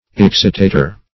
Excitator \Ex"ci*ta`tor\, n.